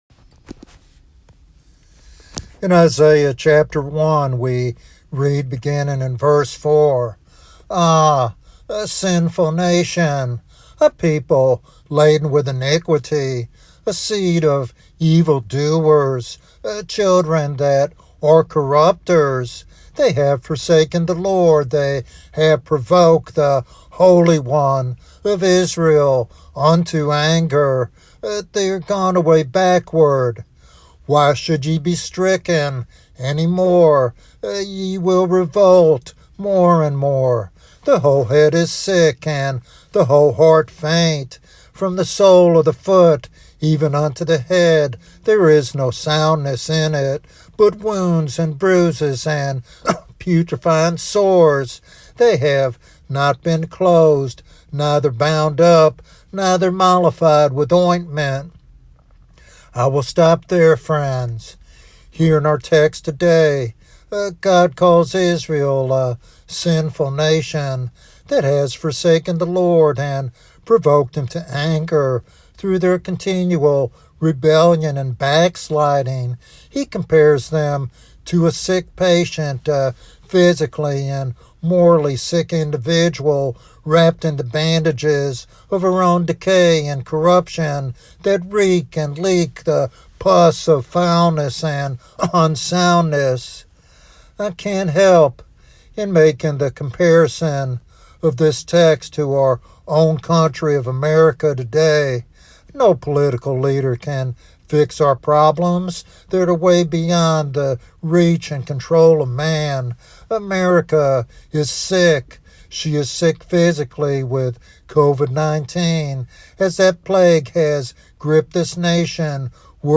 This sermon challenges listeners to examine the spiritual health of their country and their own lives.